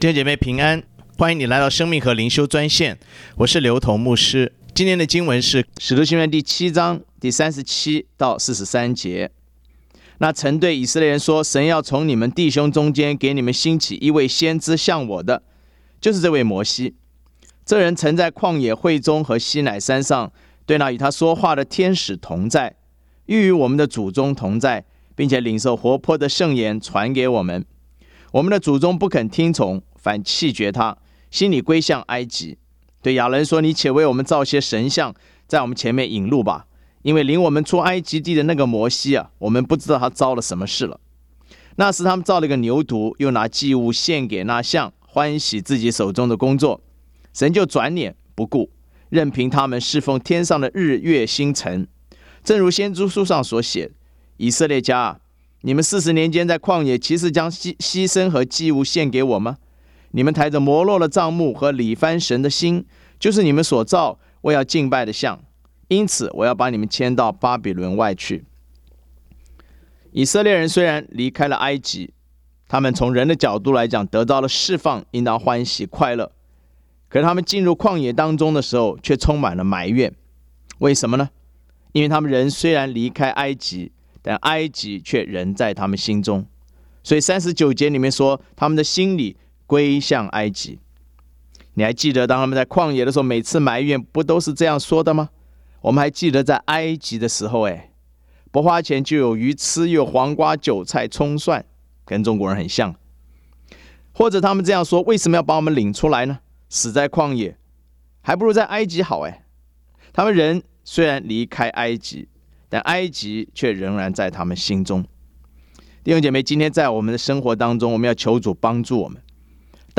藉着每天五分钟电话分享，以生活化的口吻带领信徒逐章逐节读经